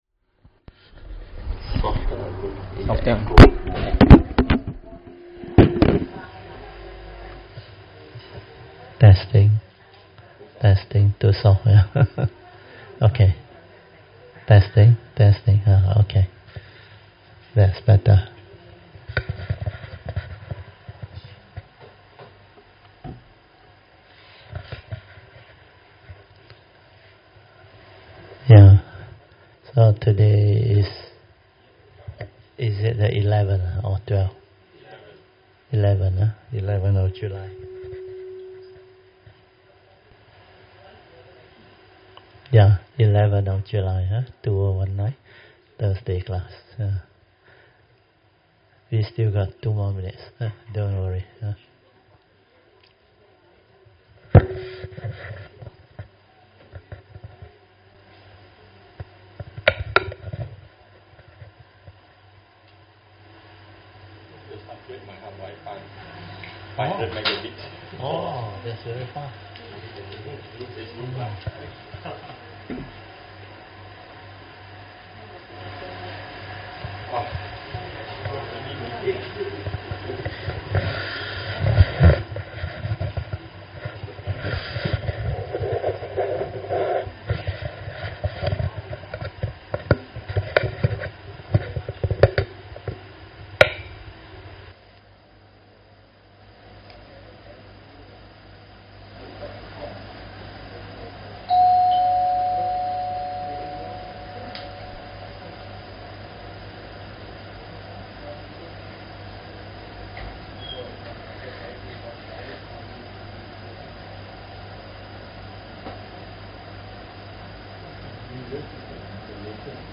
Thursday Class